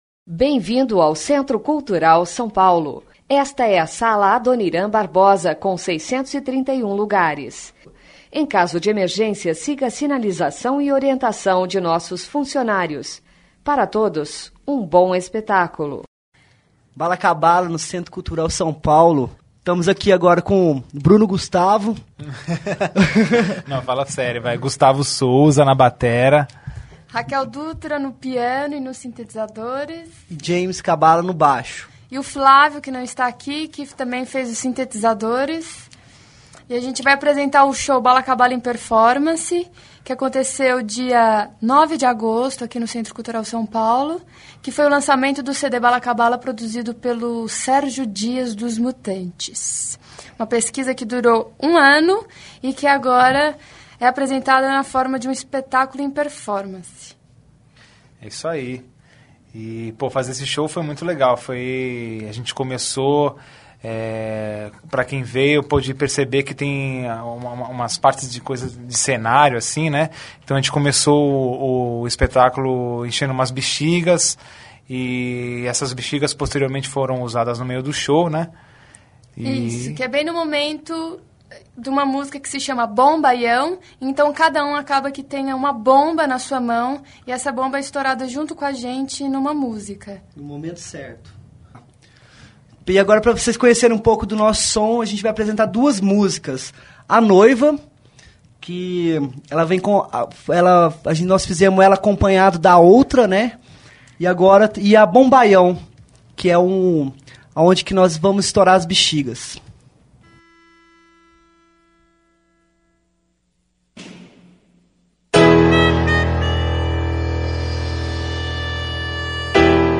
apresentou-se na sala Adoniran Barbosa